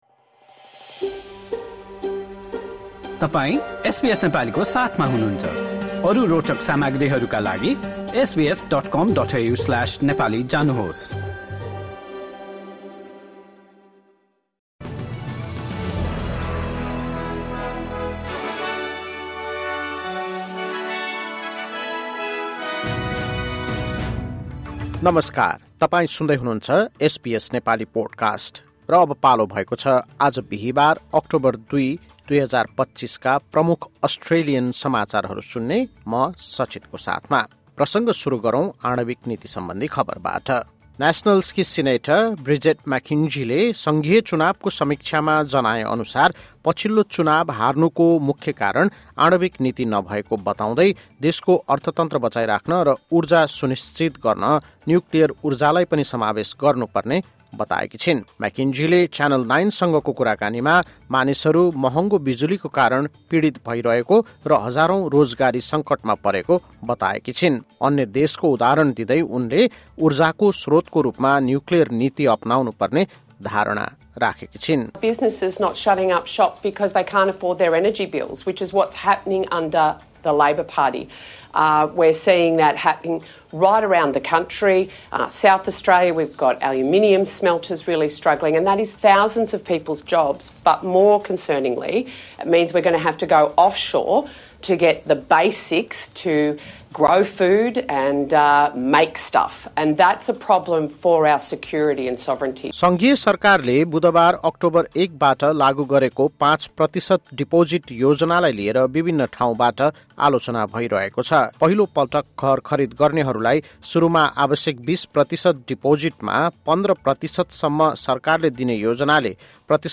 एसबीएस नेपाली प्रमुख अस्ट्रेलियन समाचार: बिहिवार, २ अक्टोबर २०२५